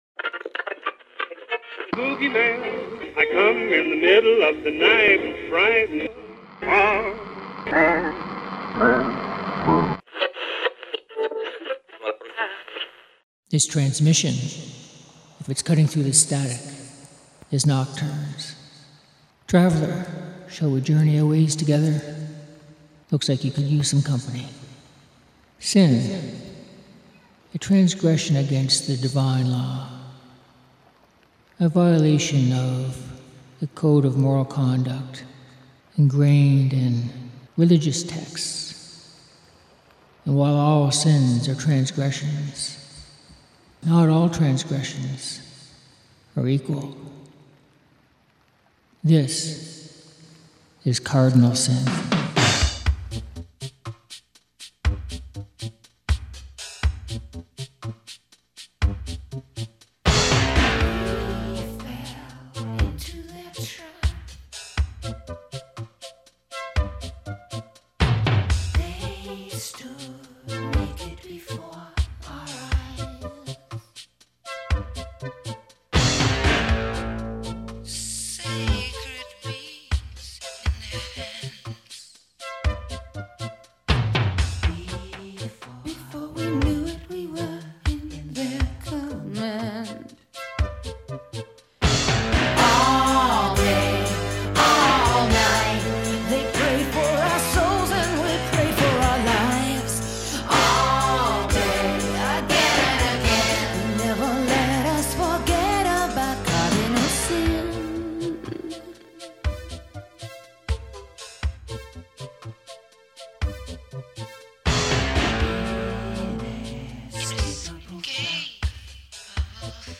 Music For Nighttime Listening